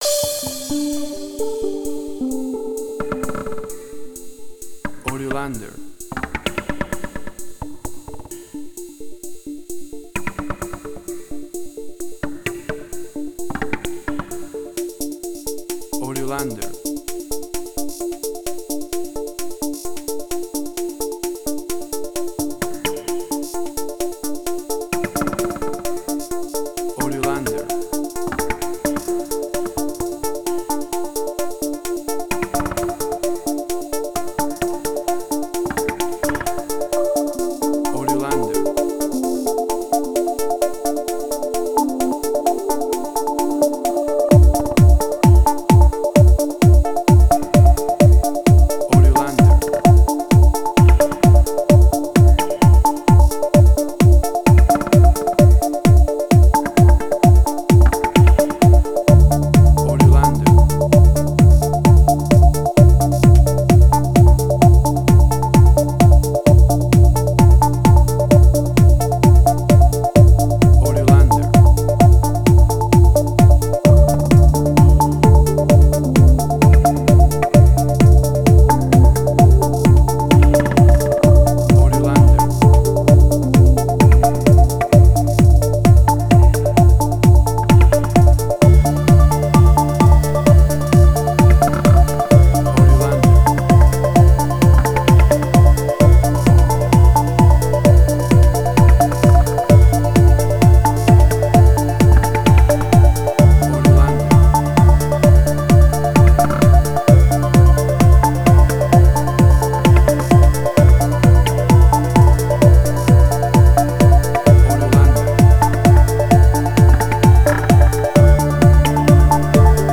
House
Tempo (BPM): 130